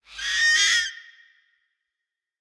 1.21.5 / assets / minecraft / sounds / mob / fox / screech3.ogg
screech3.ogg